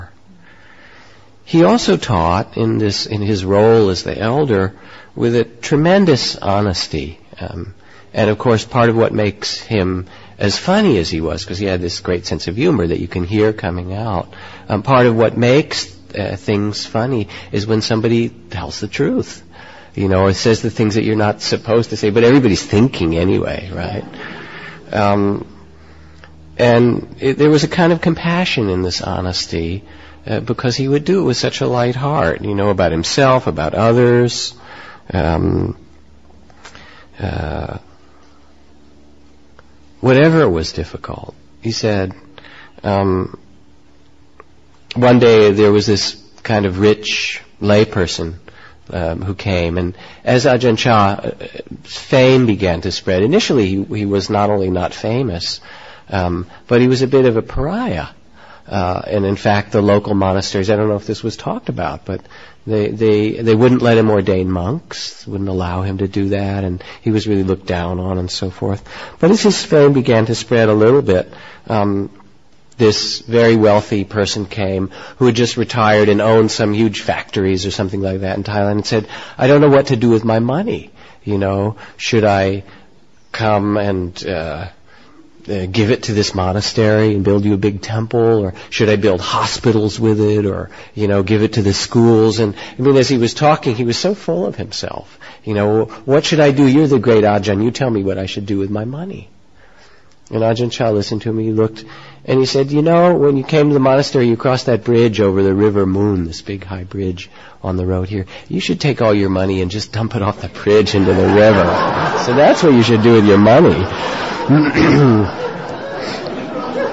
9. Story: Dump your money in the river. Told by Jack Kornfield.